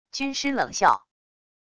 军师冷笑wav音频